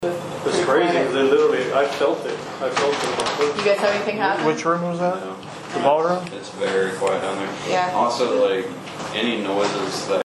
EVPs
You can hear a faint whisper in the 'background' of us talking